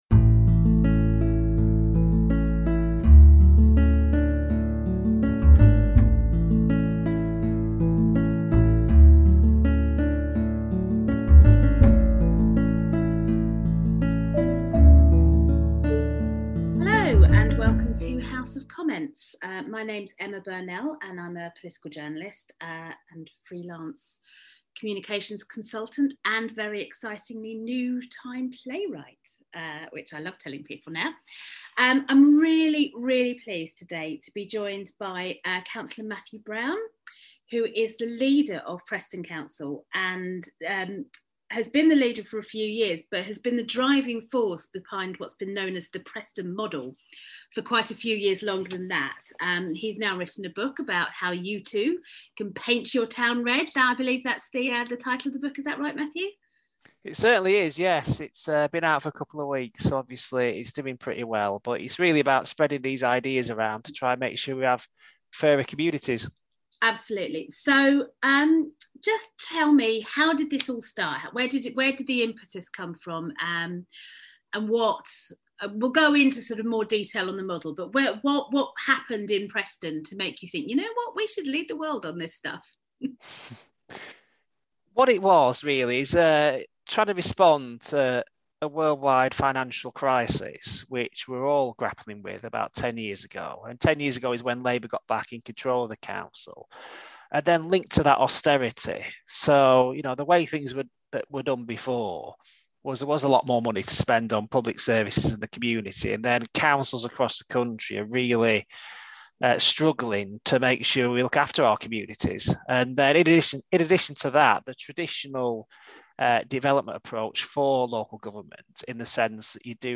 "Extreme common sense" Interview with Cllr Matthew Brown on the 'Preston Model'